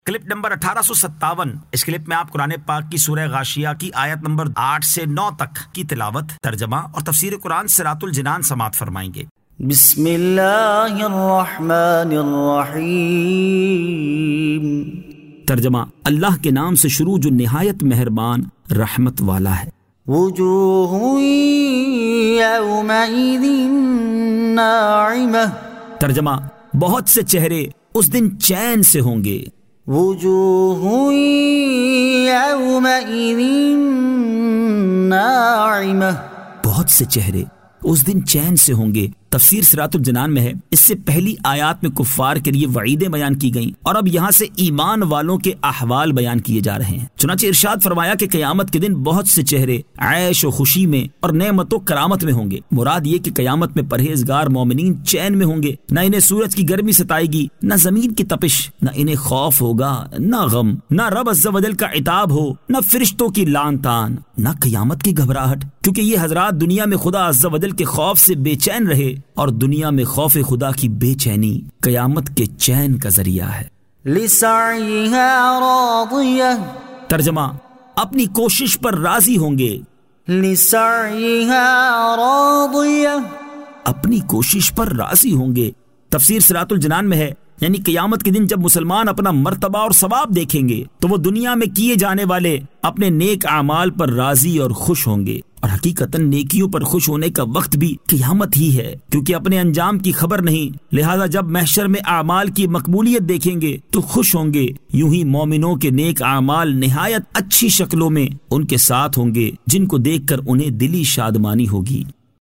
Surah Al-Ghashiyah 08 To 09 Tilawat , Tarjama , Tafseer